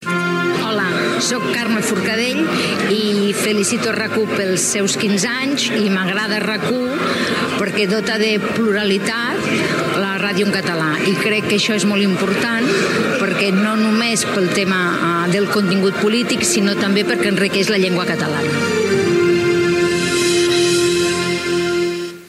Felicitacions pels 15 anys de RAC 1.
De la presidenta del Parlament de Catalunya Carme Forcadell.